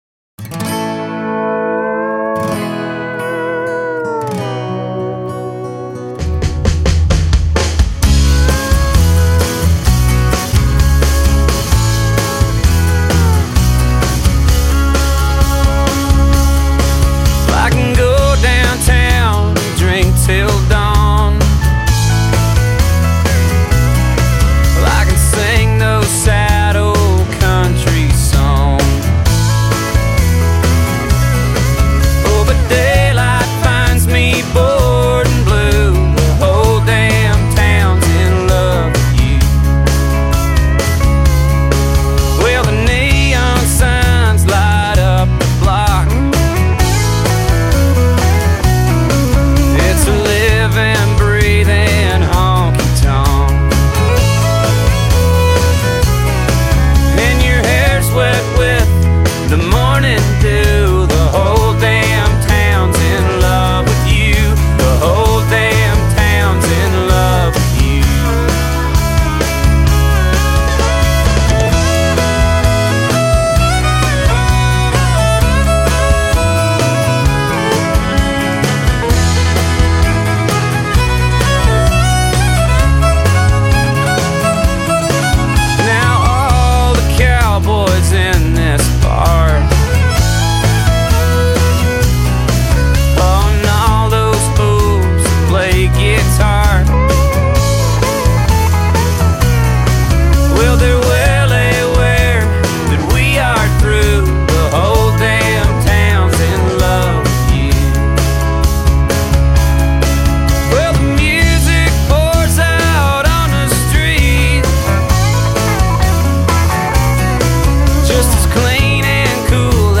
lead guitar
fiddle
bass
drums
guitar or fiddle